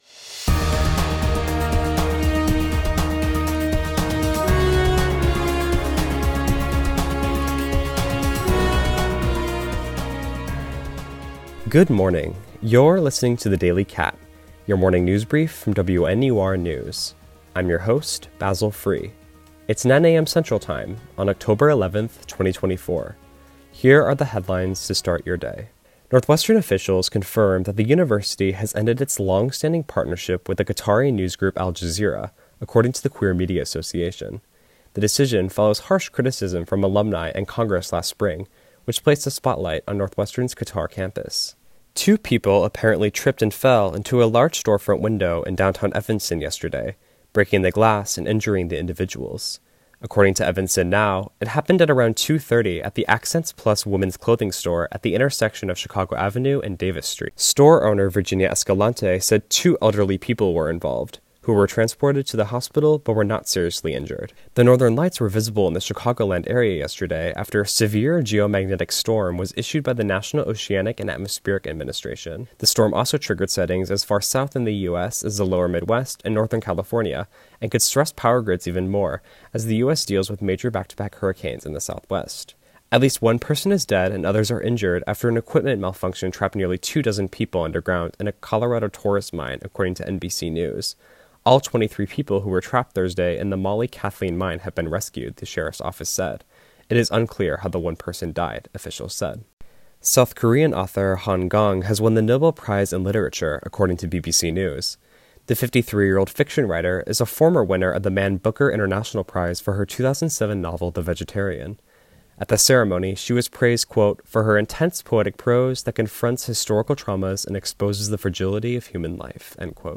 October 11, 2024: Northwestern Al Jazeera partnership ended, Evanston store damaged, Chicago northern lights, Colorado mine collapse, Nobel Prize in Literature. WNUR News broadcasts live at 6 pm CST on Mondays, Wednesdays, and Fridays on WNUR 89.3 FM.